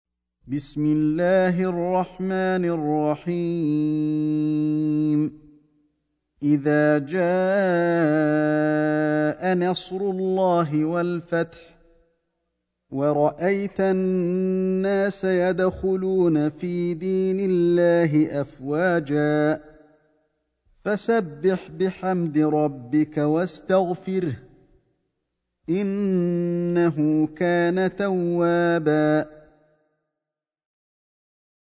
سورة النصر > مصحف الشيخ علي الحذيفي ( رواية شعبة عن عاصم ) > المصحف - تلاوات الحرمين